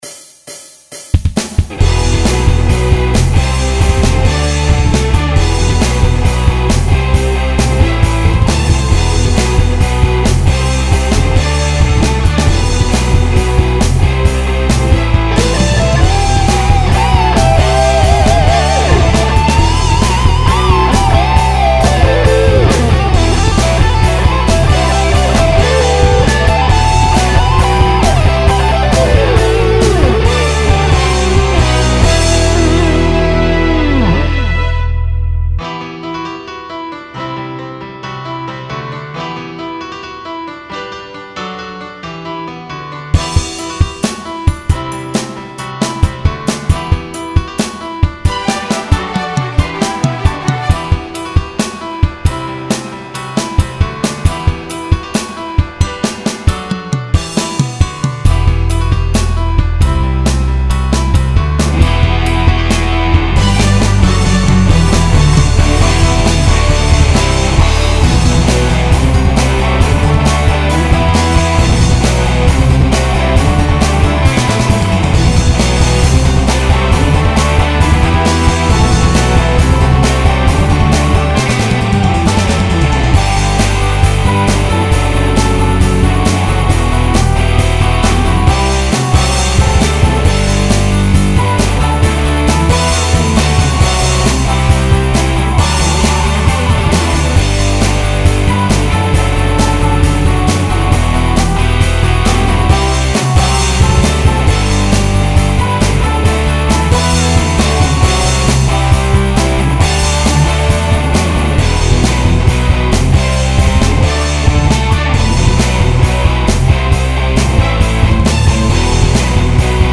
Akurat zvuk gitary je akysi grcavy - rozpliznute spodky a ploche vysky.
celkovo to znie zaujimavo, ale zvuk gitary je nezaujimavy az by som povedal, ze ma trochu taky charakter akokeby bol pod dekou
Druha polka kde sa nesoluje je lepsia, este by som tam pridal klaves mozno, solovy zvuk gitary mi tiez velmi vadil.
newrock03b.mp3